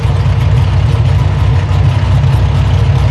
v8_idle_nascar3.wav